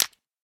minecraft / sounds / fire / ignite.ogg
ignite.ogg